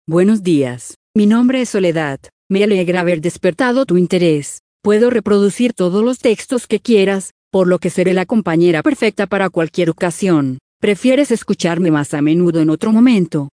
Voice Reader Studio 22 Español (Colombian) / Spanish (Colombian)
Produktprobe
Professionelle Sprachausgabe zum Vorlesen und Vertonen beliebiger Texte
Professionelle, natürlich klingende männliche und weibliche Stimmen in vielen Sprachen, die kaum mehr von einem menschlichen Sprecher zu unterscheiden sind.